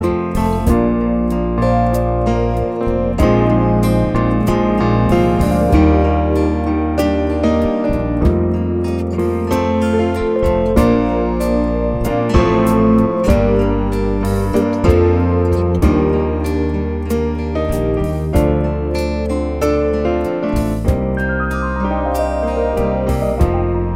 No Electric Guitar or Solo Duets 4:24 Buy £1.50